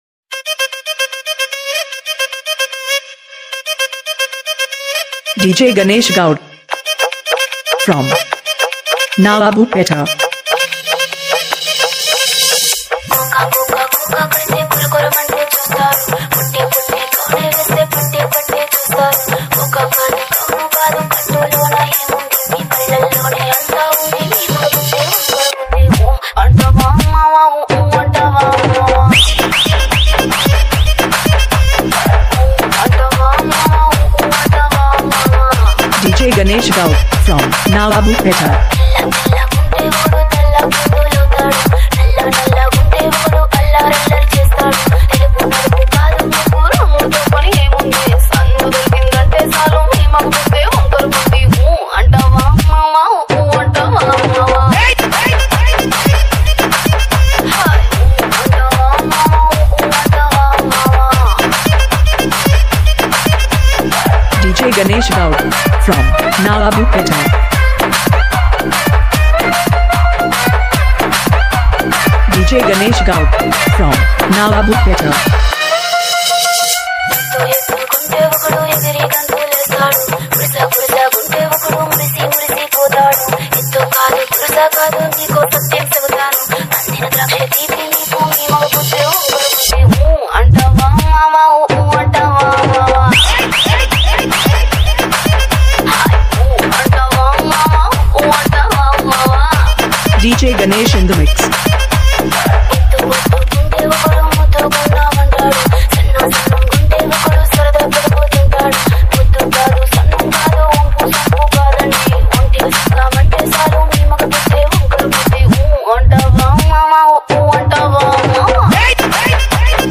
TAMIL ITEM DJ REMIX SONG